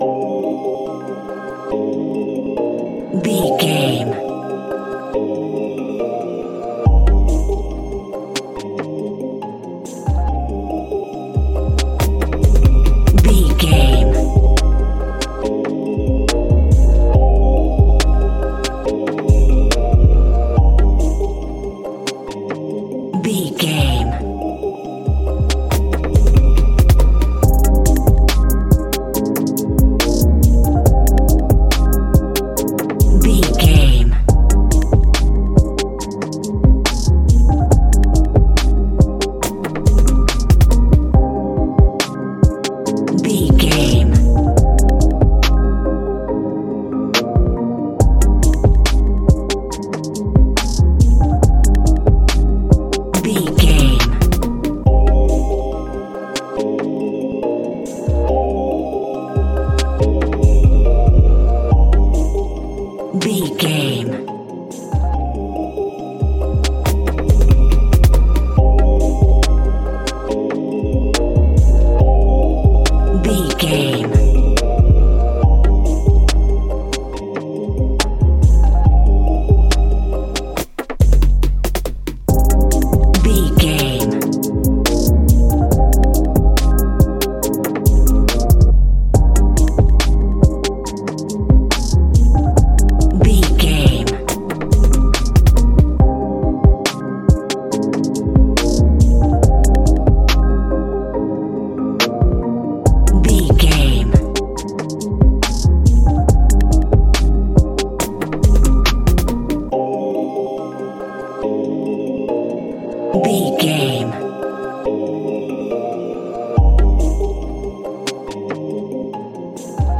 Aeolian/Minor
aggressive
intense
driving
bouncy
energetic
dark
drum machine
synthesiser